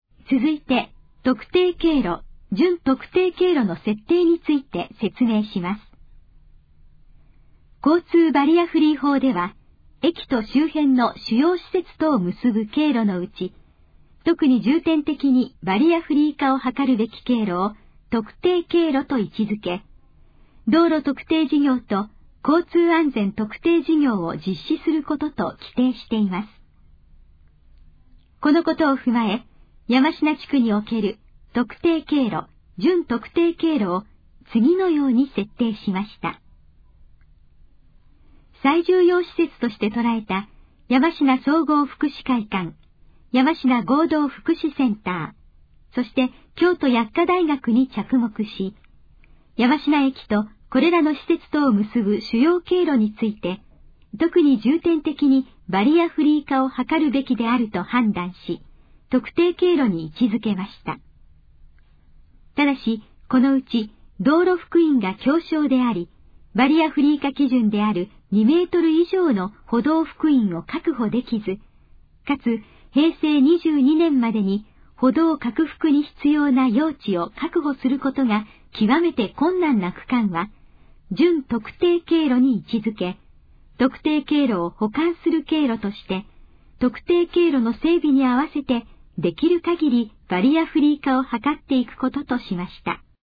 以下の項目の要約を音声で読み上げます。
ナレーション再生 約188KB